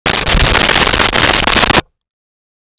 Creature Burb
Creature Burb.wav